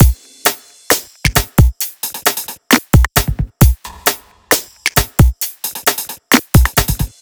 HP133BEAT1-R.wav